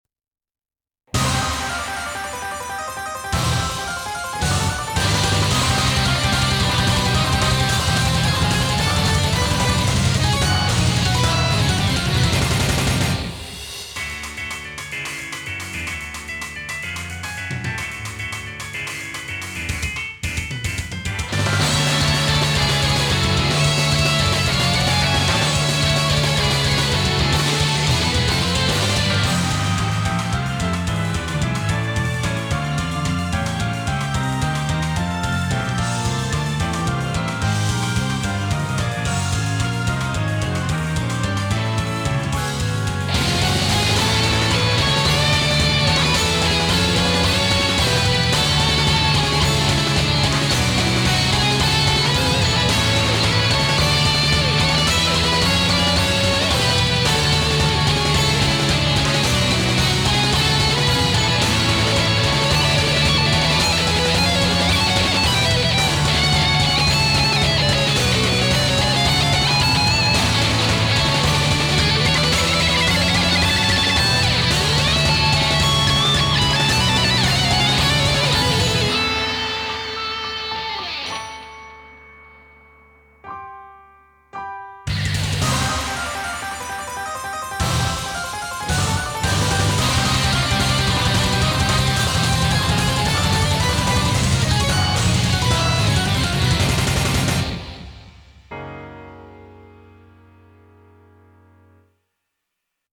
BPM220
Audio QualityPerfect (High Quality)
It's time to rock with those fast beats!